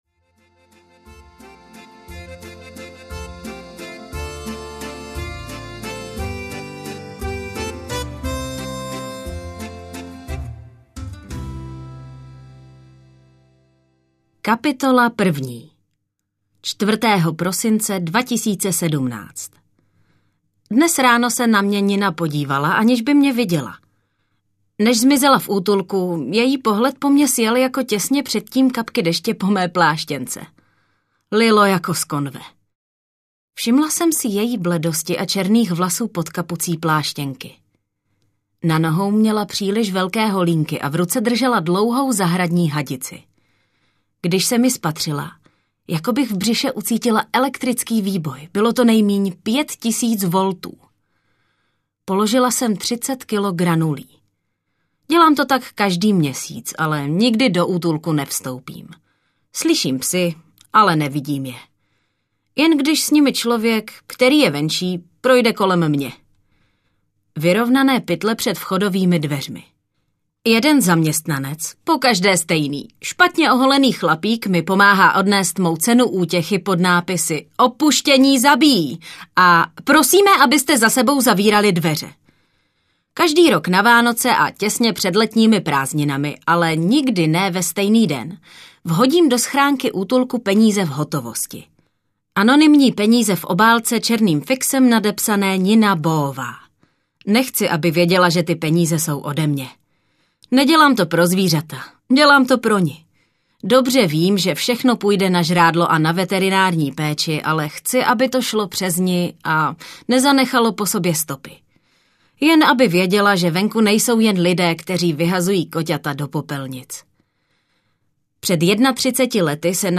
Tři audiokniha
Ukázka z knihy